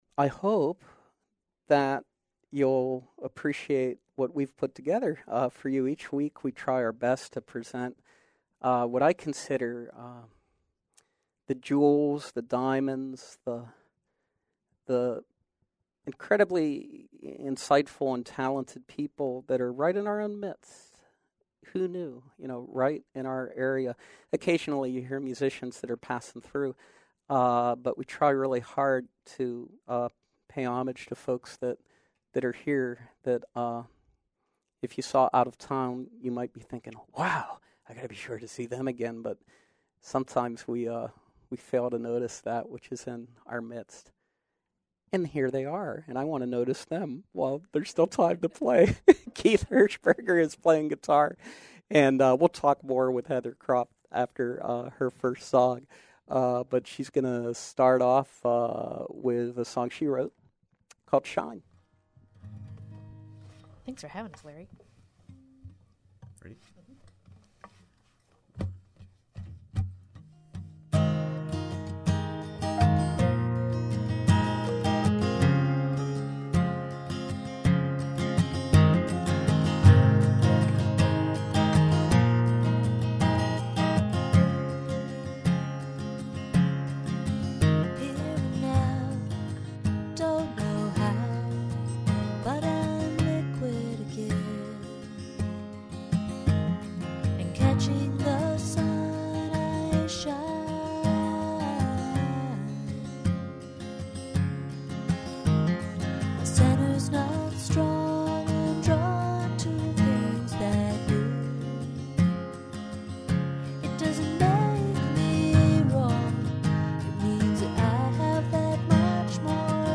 piano-driven folk, soul, and pop
guitarist